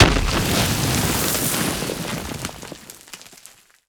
dirt4.ogg